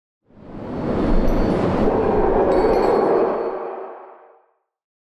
abjuration-magic-sign-circle-outro.ogg